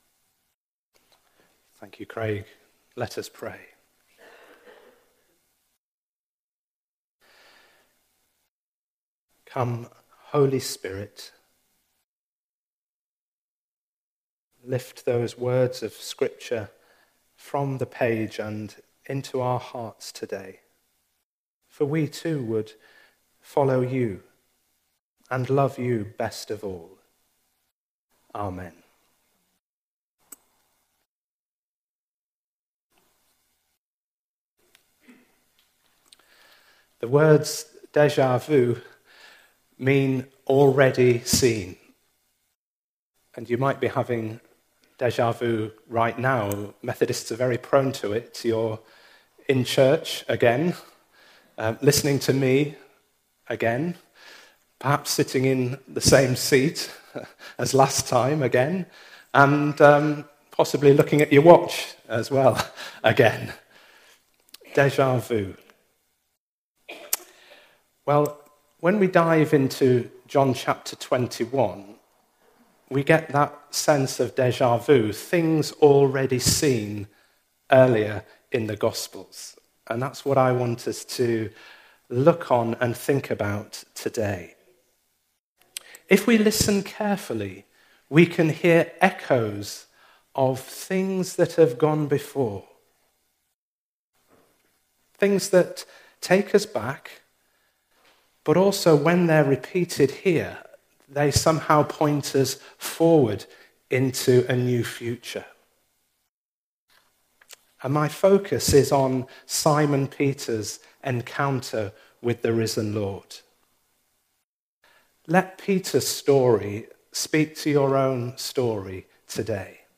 An audio version of the sermon is also available.
Passage: John 21:1-19 Service Type: Sunday Morning Today’s service was led by our minister